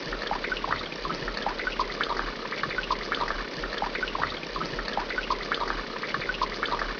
rain1_nl.wav